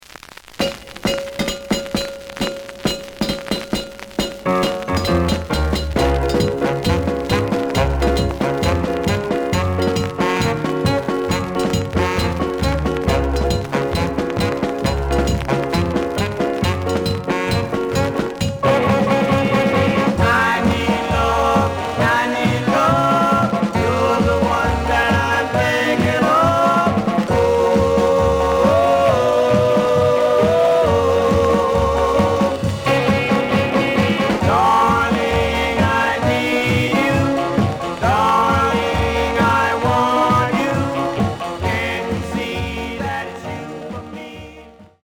試聴は実際のレコードから録音しています。
●Format: 7 inch
●Genre: Rhythm And Blues / Rock 'n' Roll